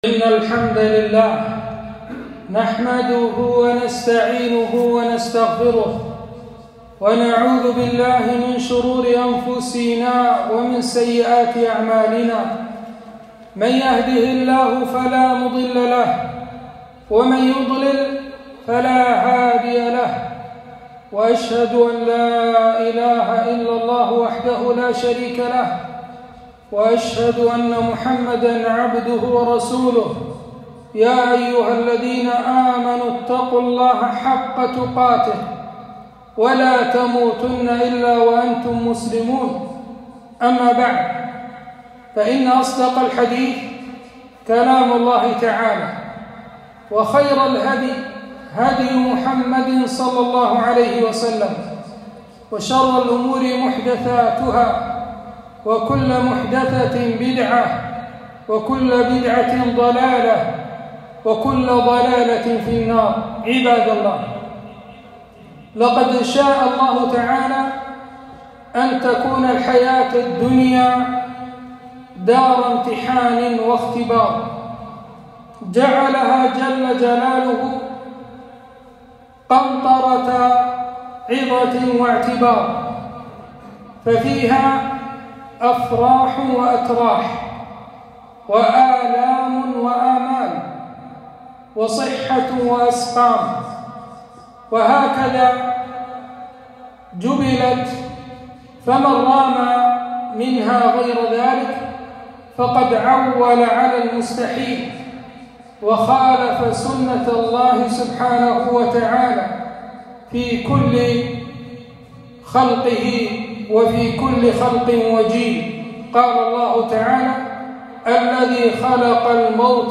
خطبة - أهمية الأخذ بالأسباب